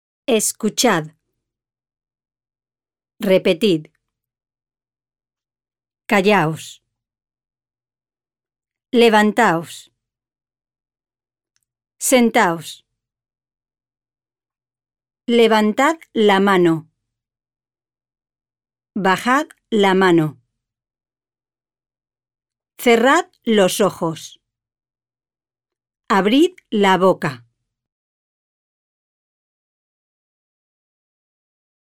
• Audio files of stories acted out by native Spanish speakers, along with the vocabulary introduced and listening exercises.
Listen to some simple commands such as stand up and sit down.